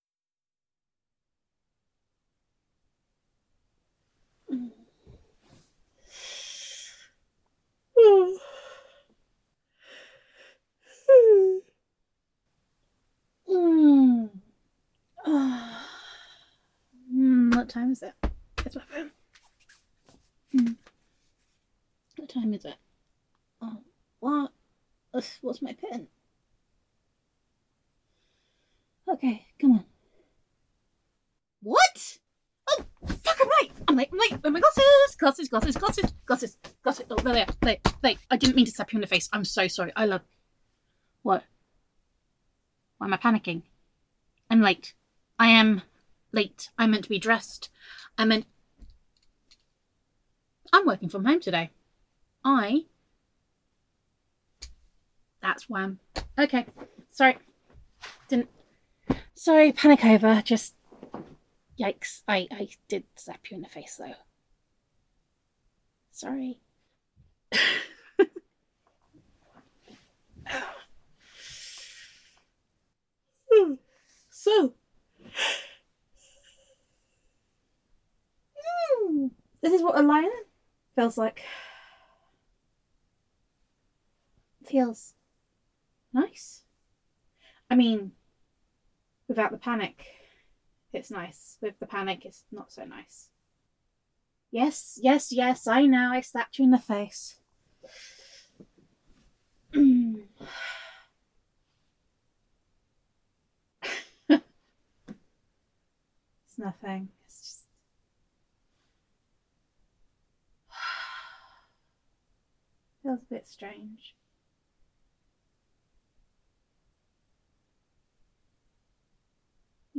[F4A] Day Two - I'm Late [Pillow Fight][Girlfriend Roleplay][Self Quarantine][Domestic Bliss][Gender Neutral][Self-Quarantine With Honey]